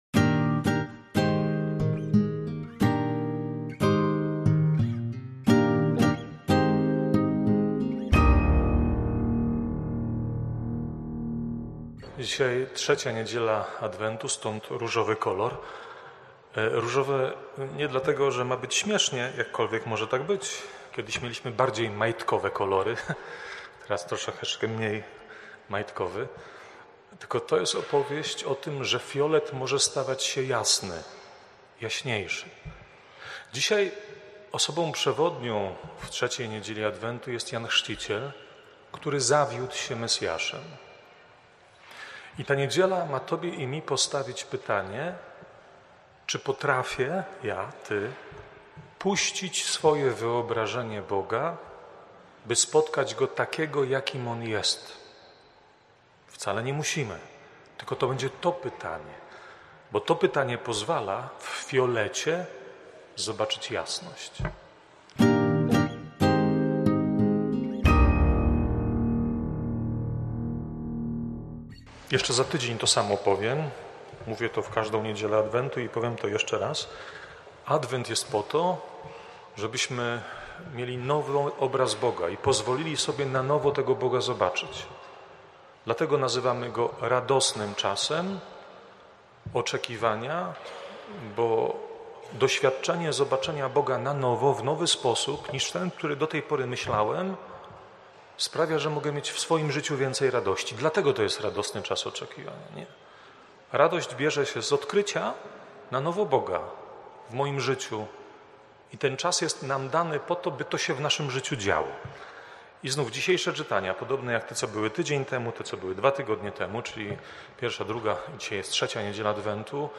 kazania.
wprowadzenie do Liturgii, oraz kazanie: